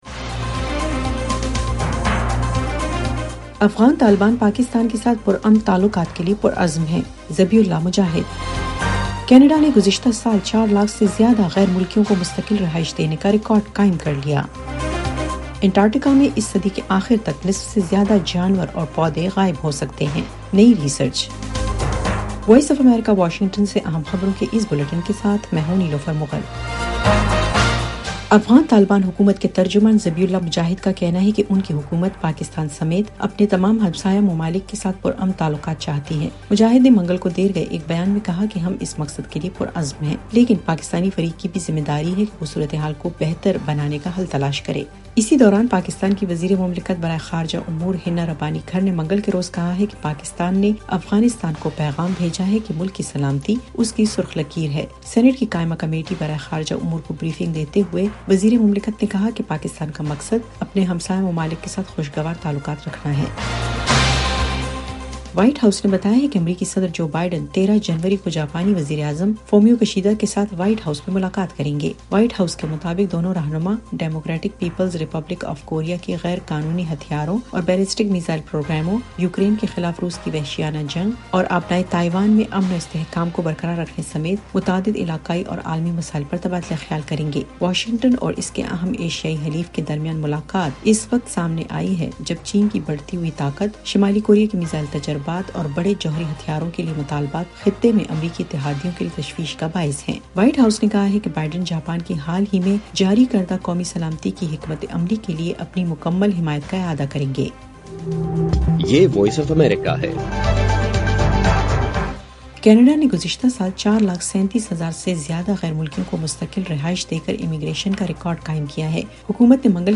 ایف ایم ریڈیو نیوز بلیٹن : شام 6 بجے